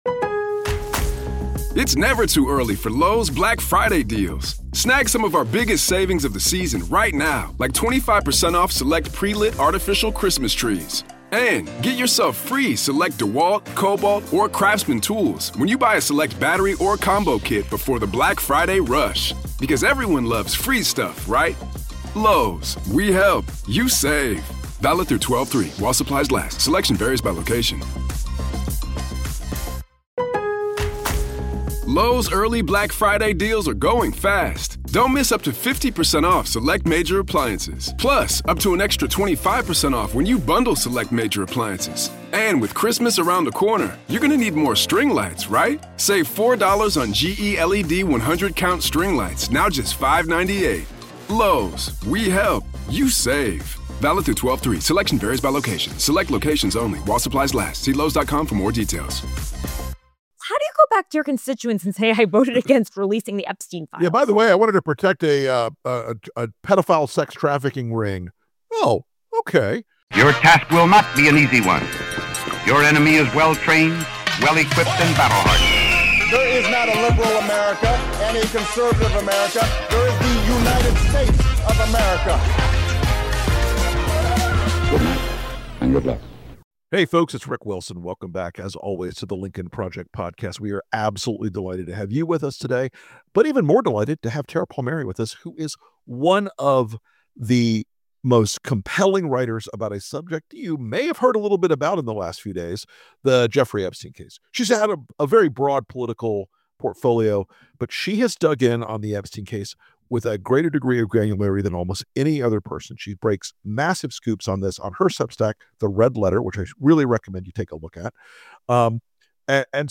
This week on The Lincoln Project Podcast, Rick Wilson sits down with investigative reporter Tara Palmeri (The Tara Palmeri Show, The Red Letter) for a brutal, no-BS breakdown of the new Jeffrey Epstein emails naming Donald Trump, what they reveal about his long-denied ties to ...